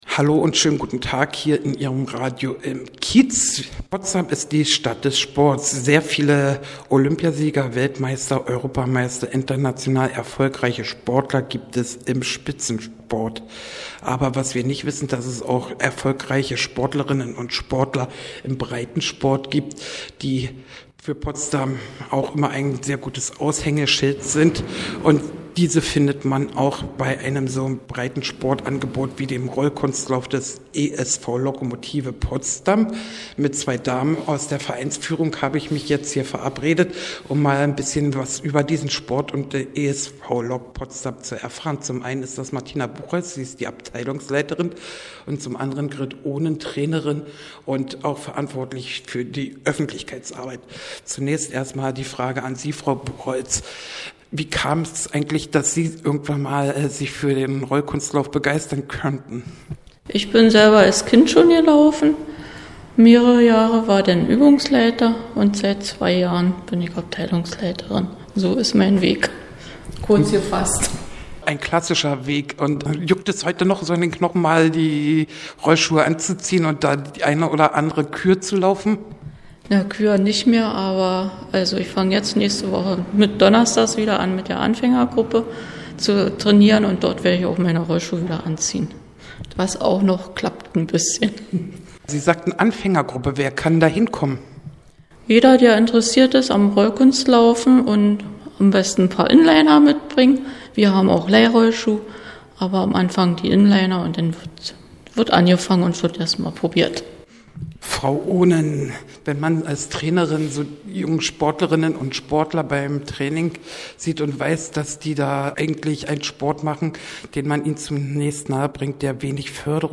Interview: Radio im Kiez - Oktober 2013.mp3
InterviewzumThemaRollkunstlaufbeimESVLokPotsdamSchlaatz_de.mp3